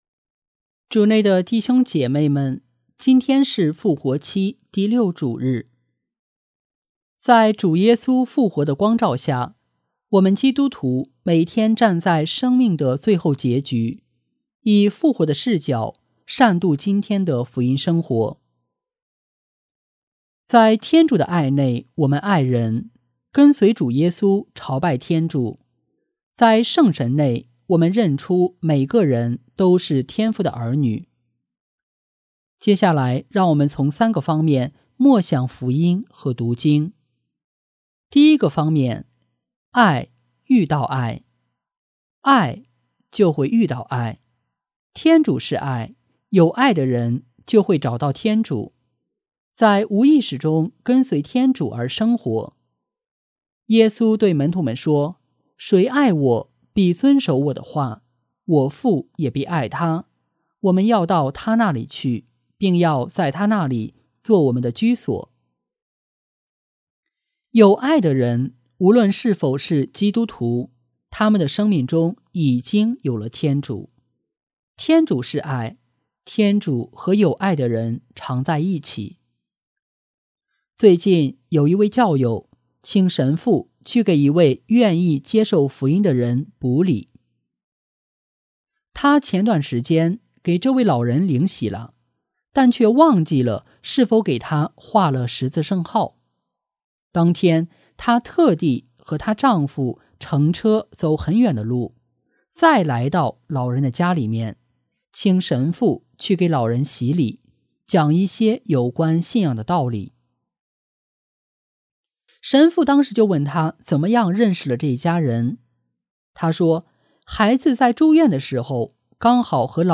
【主日证道】| 爱遇到爱（丙-复活期第6主日）